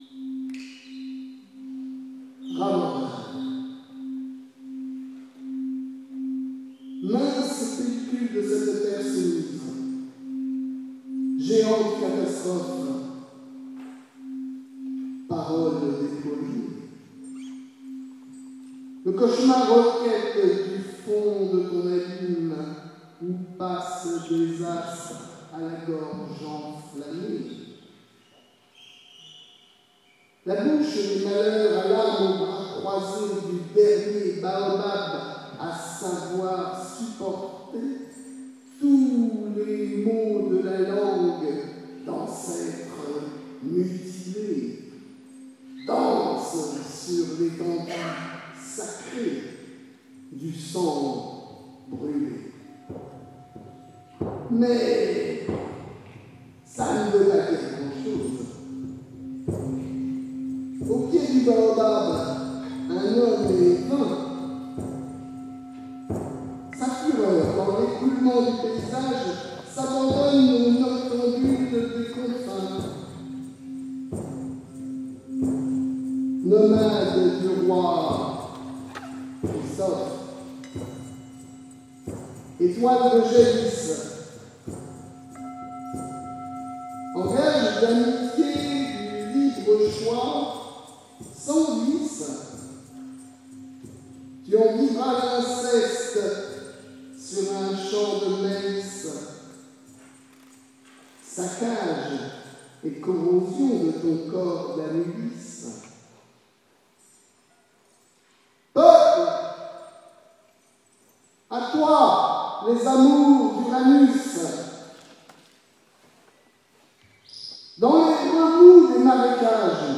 accompagné par les musiciens
SALLE DE LA LEGION D’HONNEUR à 93200 SAINT DENIS